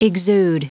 Prononciation du mot exude en anglais (fichier audio)
Prononciation du mot : exude